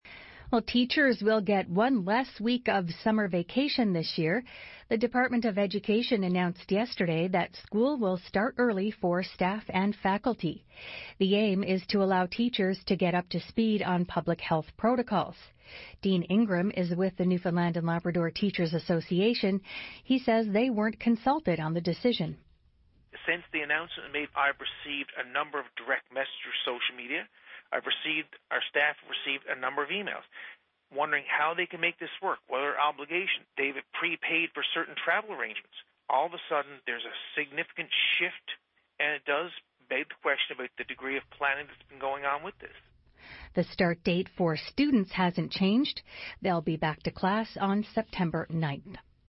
Media Interview - CBC 7am News - July 21, 2020